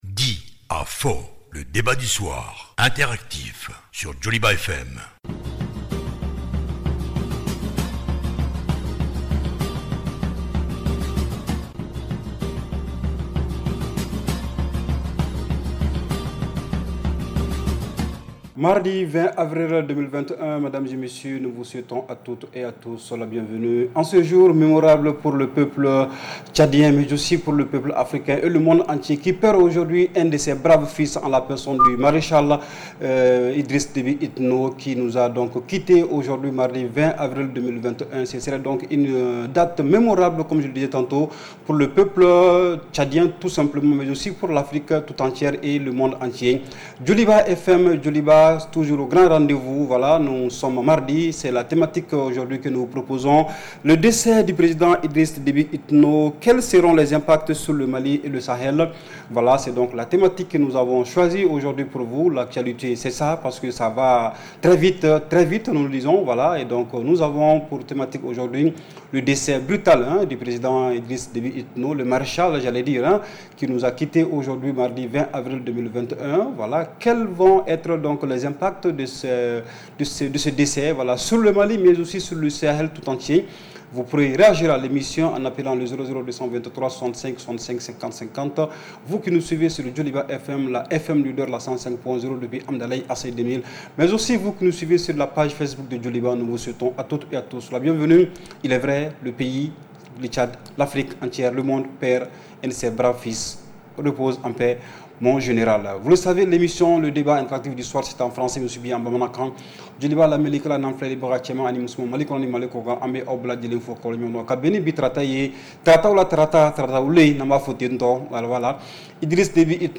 REPLAY 20/04 – « DIS ! » Le Débat Interactif du Soir